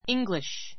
íŋɡliʃ